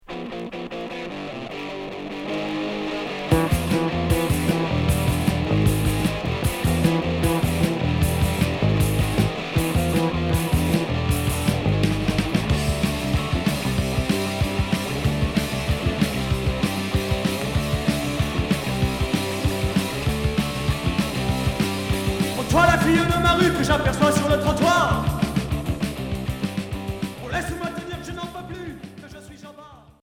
Rock hard punk